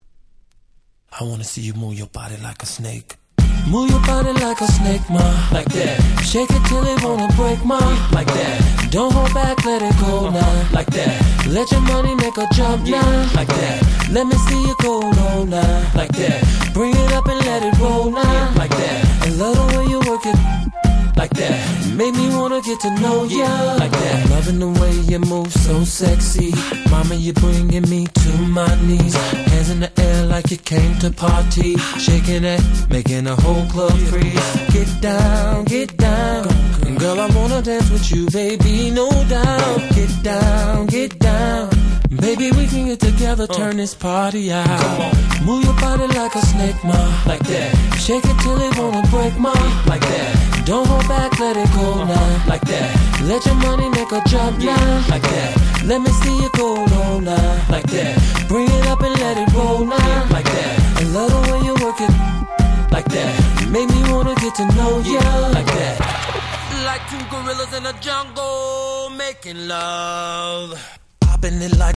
03' Super Hit R&B !!